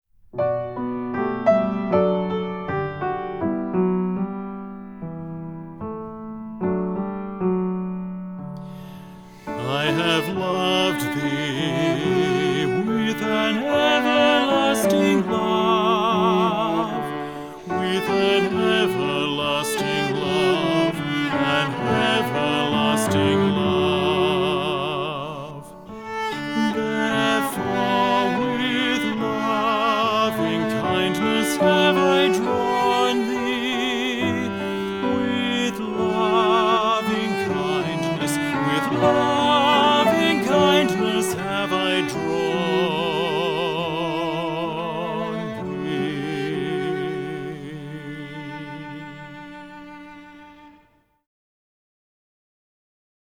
• 26 songs and 25 dialogue recordings.